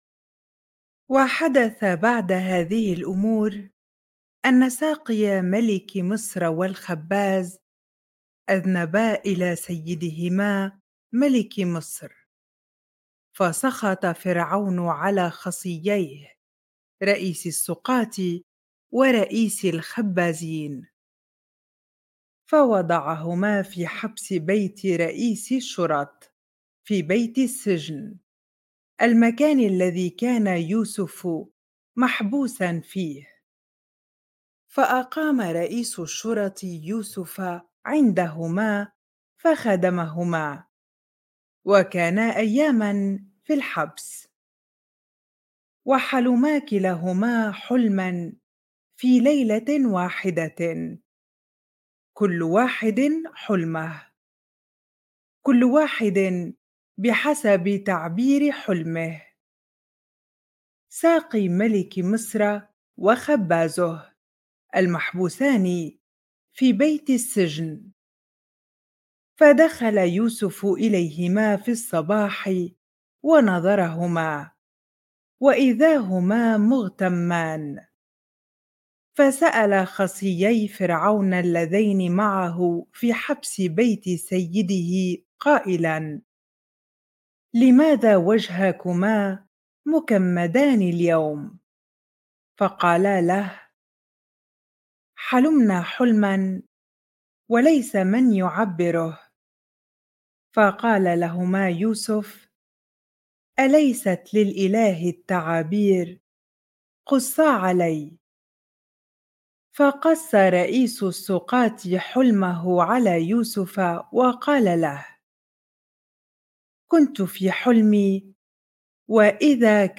bible-reading-genesis 40 ar